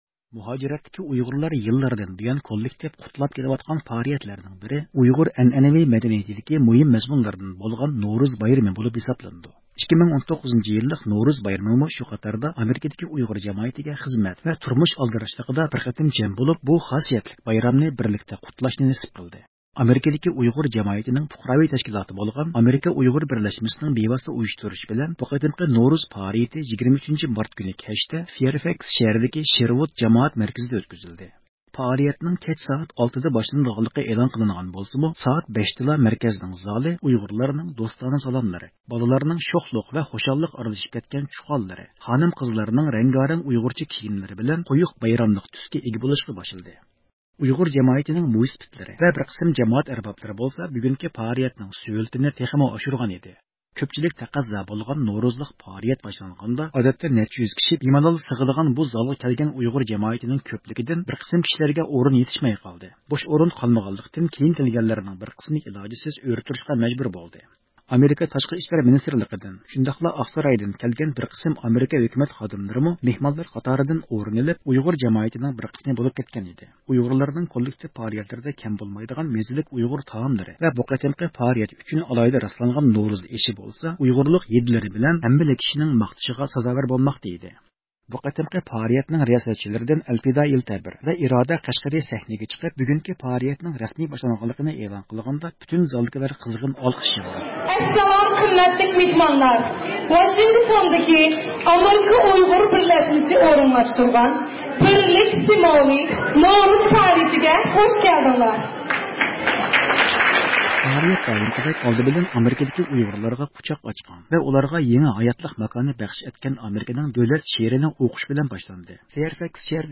ئامېرىكا ئۇيغۇر بىرلەشمىسىنىڭ بىۋاسىتە تەشكىللىشى بىلەن بۇ قېتىمقى نورۇز پائالىيىتى 23-مارت كۈنى كەچتە فايىرفاكس شەھىرىدىكى شېرۋۇد جامائەت مەركىزىدە ئۆتكۈزۈلدى.